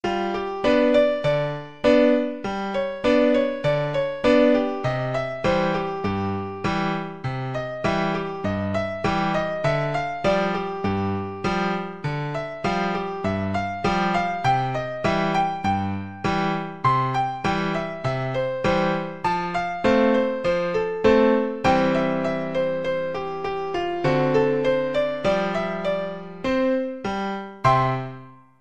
No parts available for this pieces as it is for solo piano.
A beginners ragtime piece
4/4 (View more 4/4 Music)
Giocoso
Original version for Piano
Jazz (View more Jazz Piano Music)